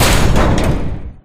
Gate2.ogg